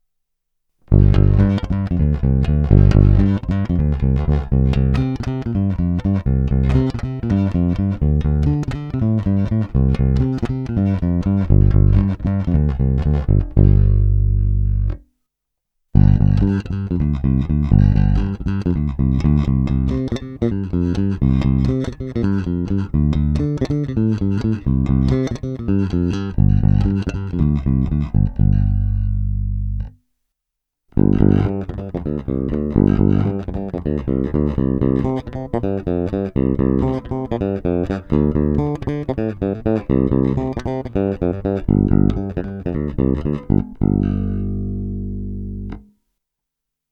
Opět nejdříve jen rovnou do zvukovky a ponecháno bez úprav, jen normalizováno.